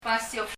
よ～く聴くと、やはり[sh]の音（[ʃɔ])ではなくて、[sio] あるいは [siyo] と聞こえます。
« flower 花 waterfall 滝 » place 場所 basio [bʌsi(y)o] よ～く聴くと、やはり[sh]の音（[ʃɔ])ではなくて、[sio] あるいは [siyo] と聞こえます。